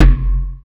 SouthSide Kick Edited (12).wav